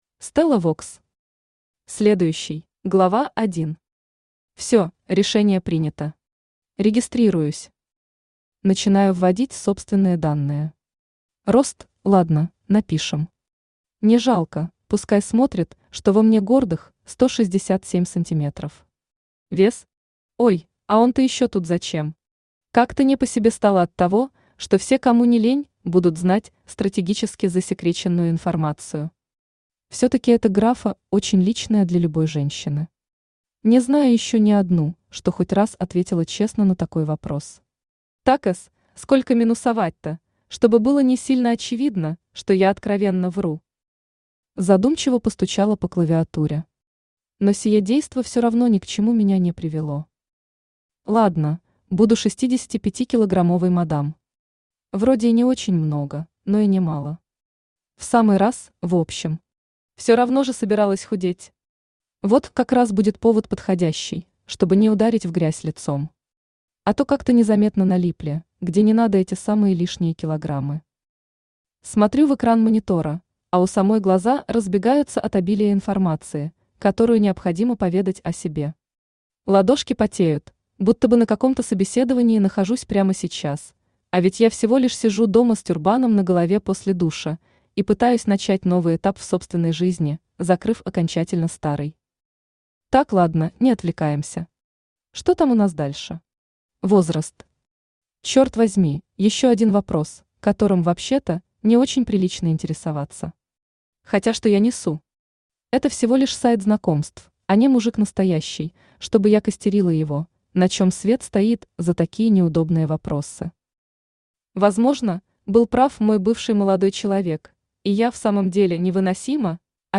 Aудиокнига Следующий Автор Стелла Вокс Читает аудиокнигу Авточтец ЛитРес.